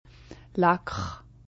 [kχ] stimmlose velare-uvulare Affrikate